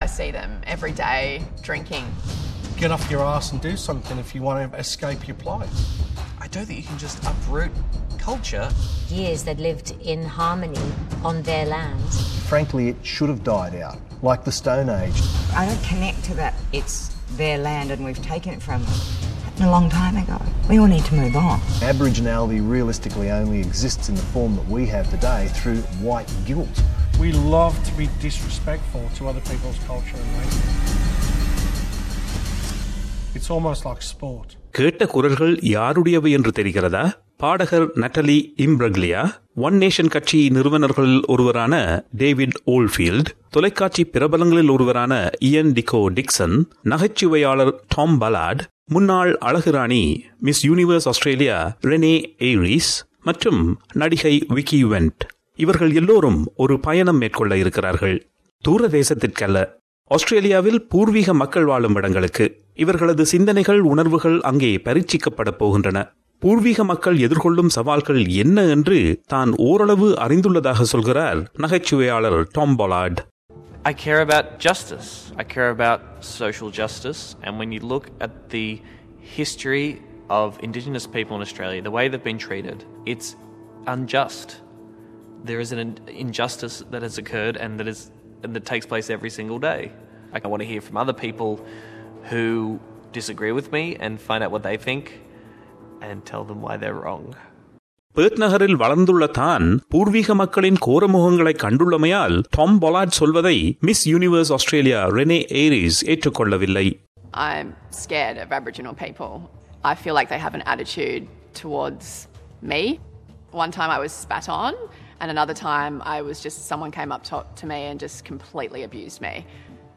[Listener warning] Aboriginal and Torres Strait Islander listeners are advised that the following story may contain voices and names of people who have died.